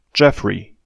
Geoffrey [ˈdʒɛfri] (